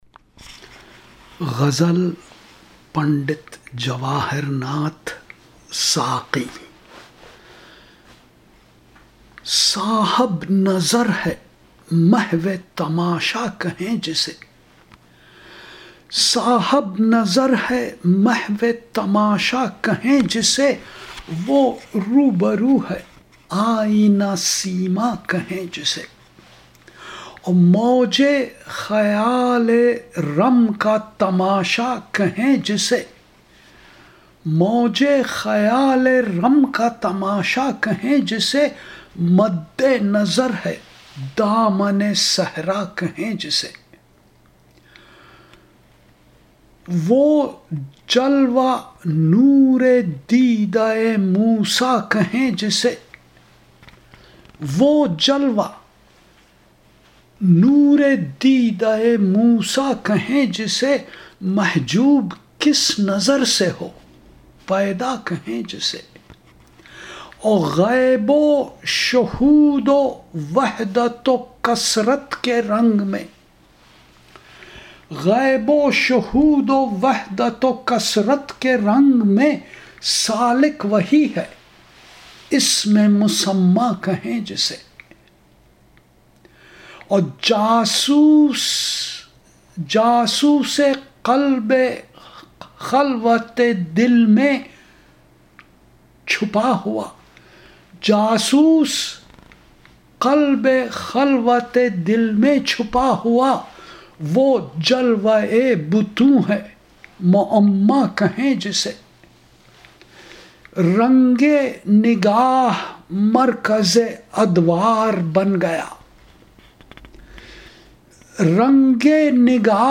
Recitation